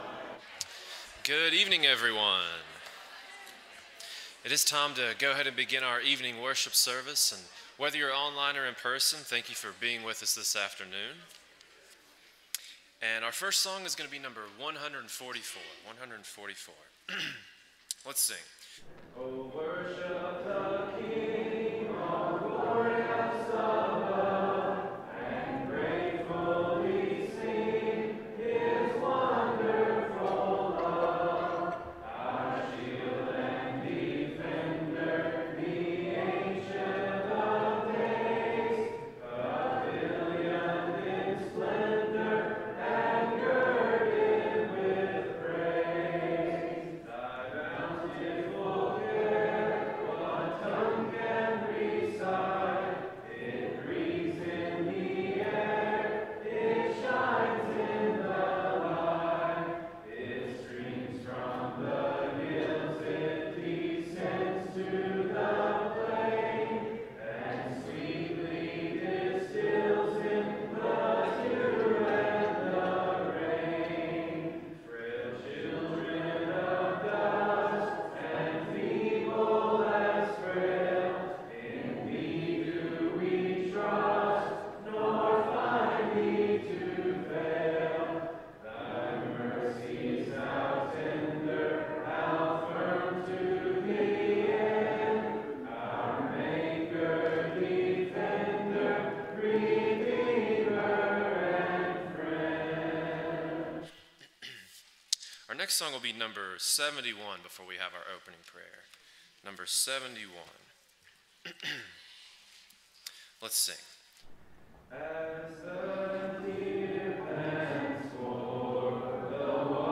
Ecclesiastes 3:4, English Standard Version Series: Sunday PM Service